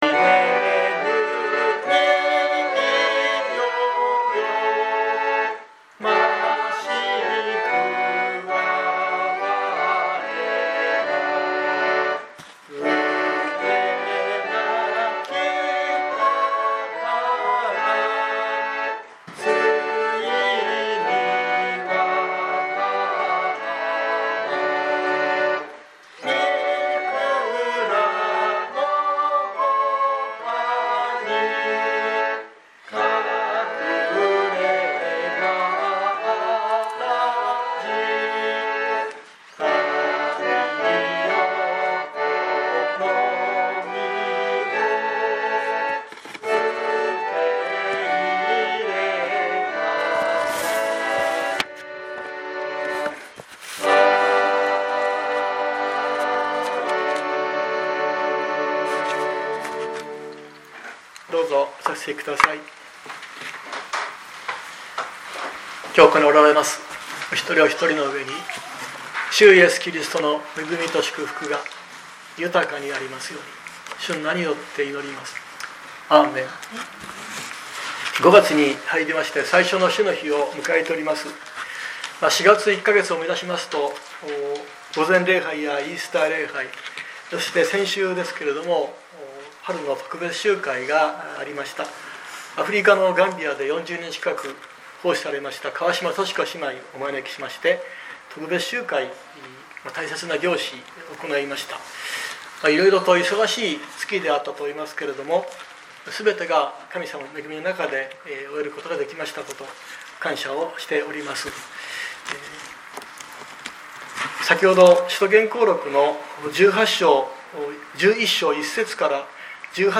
2025年05月04日朝の礼拝「神の御業が働いて」熊本教会
熊本教会。説教アーカイブ。